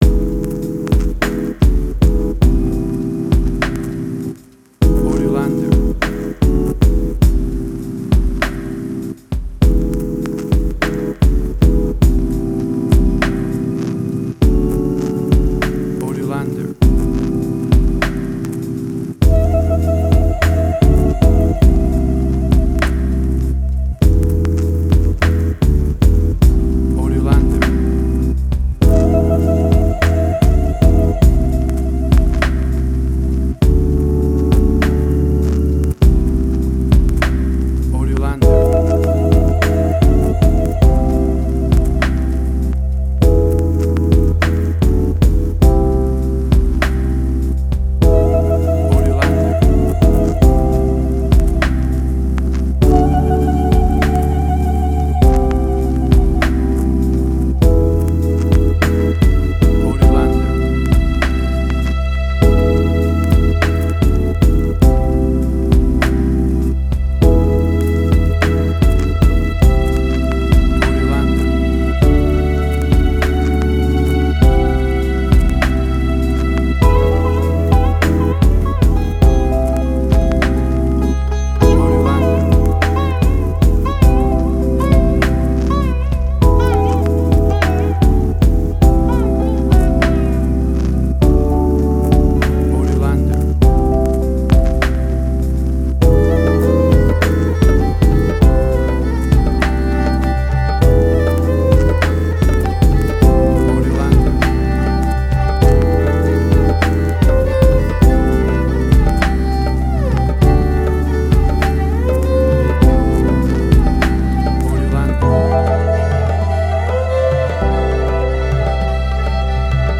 Indian Fusion
emotional music
Tempo (BPM): 100